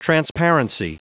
Prononciation du mot transparency en anglais (fichier audio)
Prononciation du mot : transparency
transparency.wav